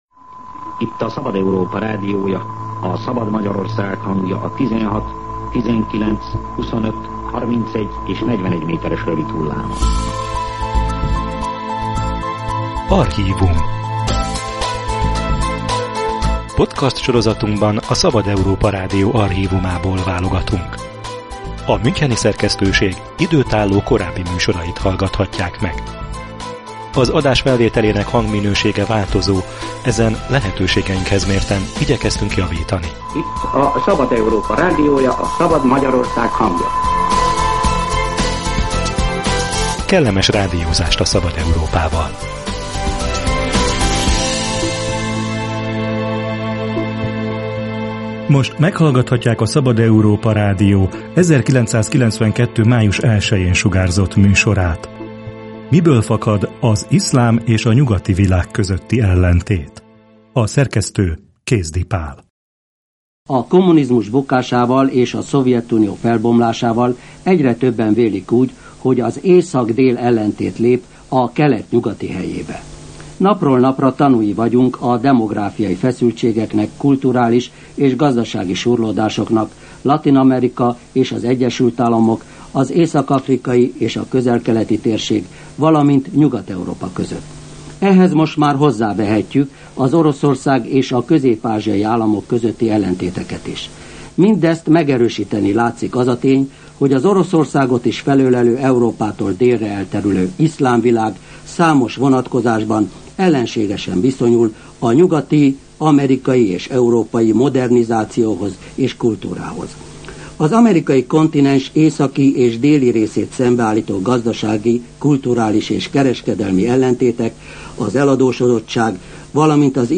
Fundamentalista nézetek és jogvédelem — archív műsor az iszlám és a nyugati világ ellentétéről
A gazdasági kapcsolatokban látták harminc évvel ezelőtt annak biztosítékát, hogy a muzulmánok és a Nyugat szembenállását féken tartsák - derült ki a Szabad Európa Rádió 1992-ben sugárzott műsorából. Szó esik a mohamedán világ három vereségéről, a nyugati értékek terjedéséről, az iszlám fordulatról.